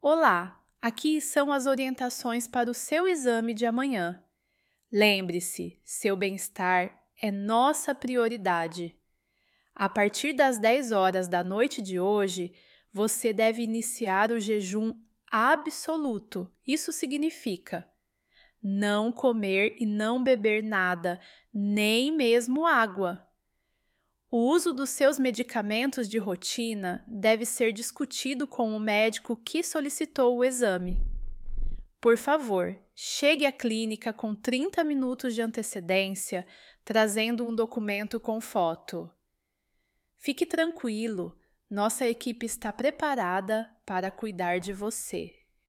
Tessdocs Speech
**Enlace del Audio Generado: **